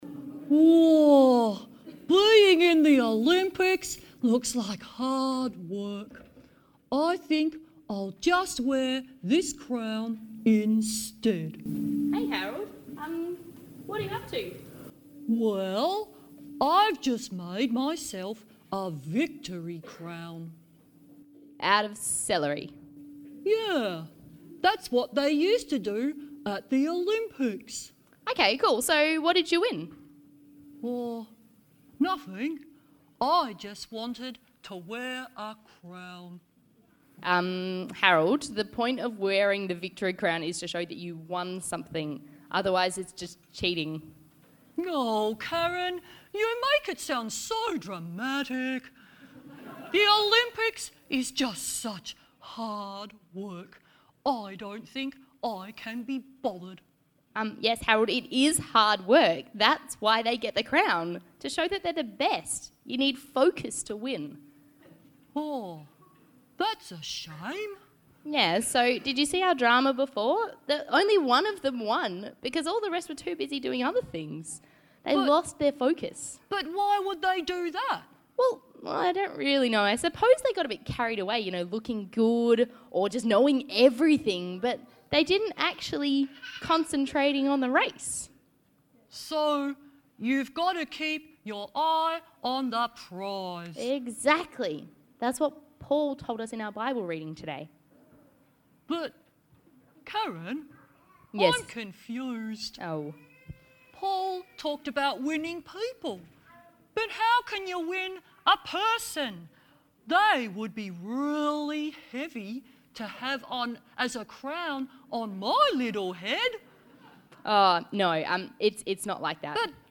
Bible Passage